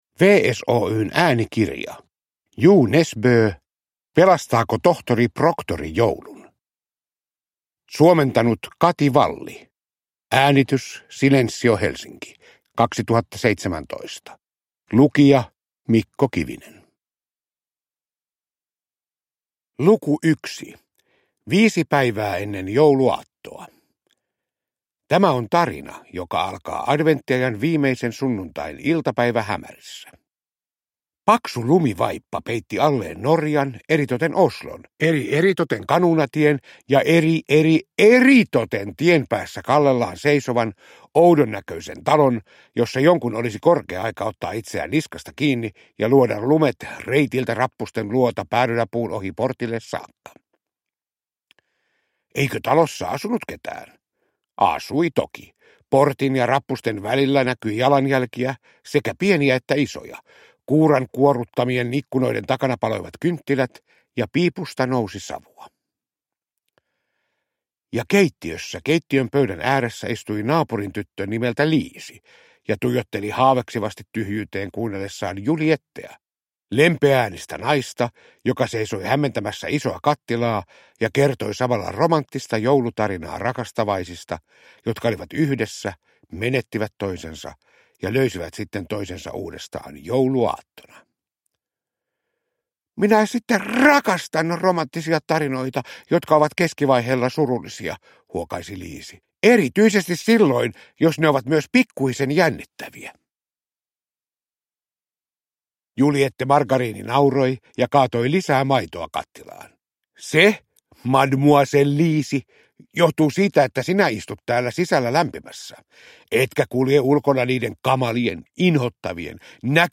Pelastaako tohtori Proktori joulun? – Ljudbok – Laddas ner